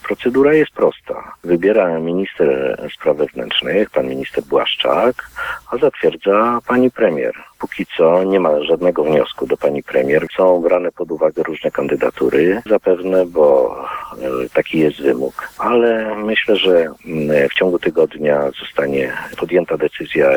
– To premier odpowiada za powołanie wojewody. Do tej pory, żaden wniosek do kancelarii jeszcze nie wpłynął – mówi poseł Michał Jach.